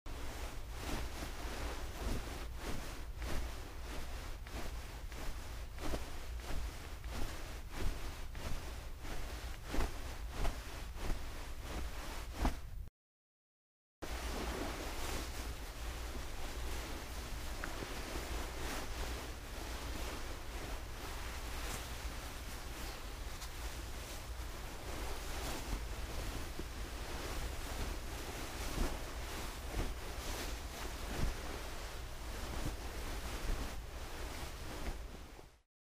На этой странице собраны разнообразные звуки одежды: от шуршания нейлона до щелчков ремней.
Шуршание одежды во время ходьбы и движения